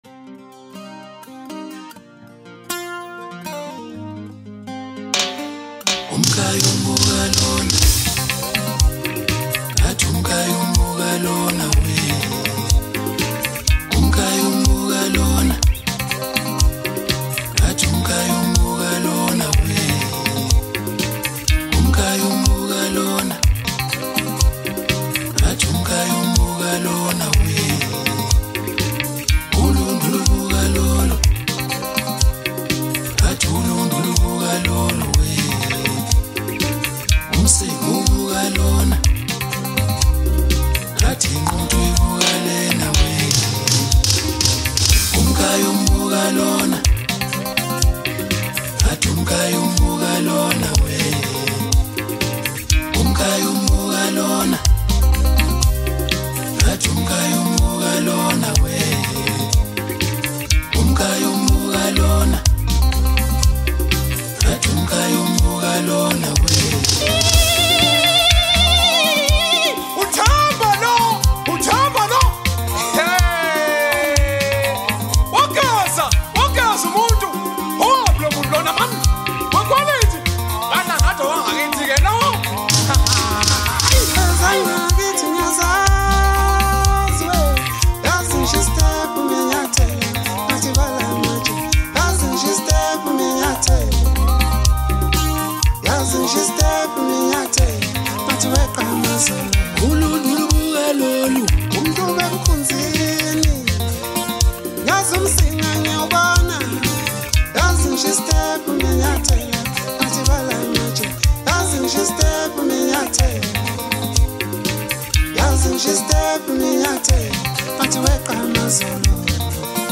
Home » DJ Mix » Lekompo » Maskandi